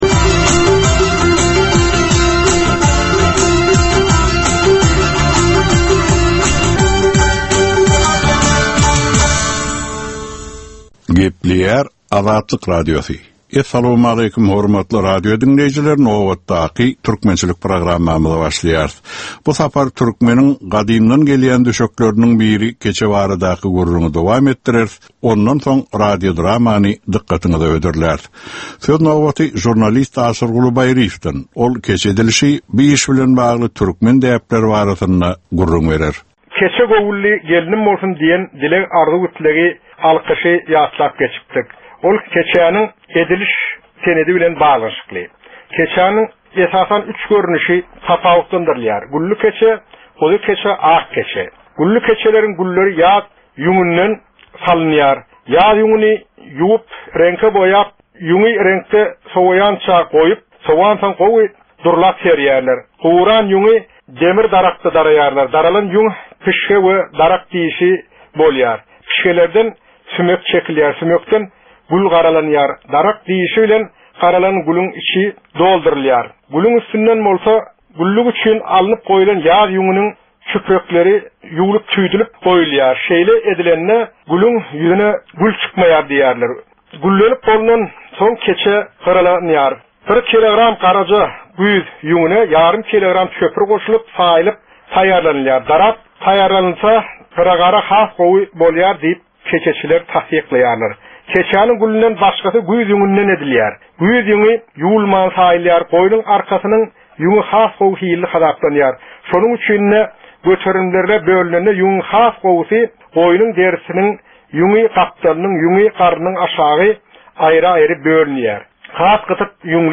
Türkmen halkynyn däp-dessurlary we olaryn dürli meseleleri barada 10 minutlyk ýörite geplesik. Bu programmanyn dowamynda türkmen jemgyýetinin su günki meseleleri barada taýýarlanylan radio-dramalar hem efire berilýär.